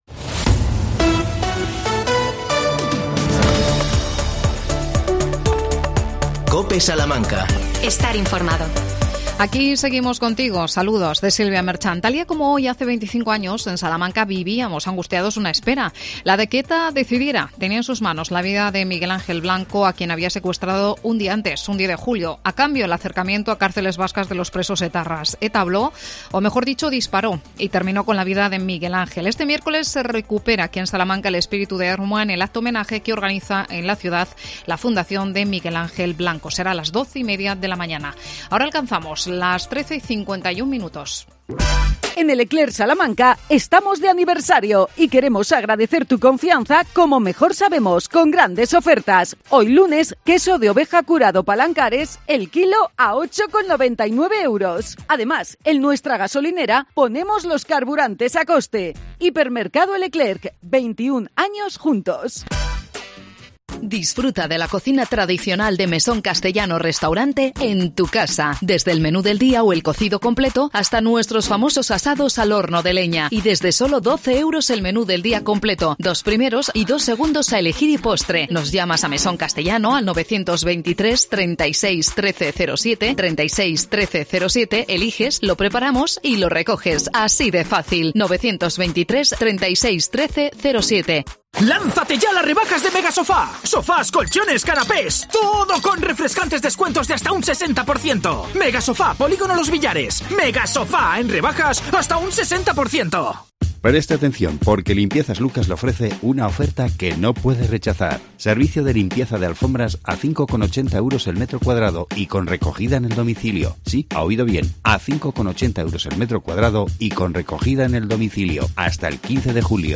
AUDIO: Primer gran incendio en la provincia. Entrevistamos al alcalde de Sequeros Mauricio Angulo.